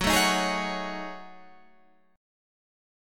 Listen to F#m6add9 strummed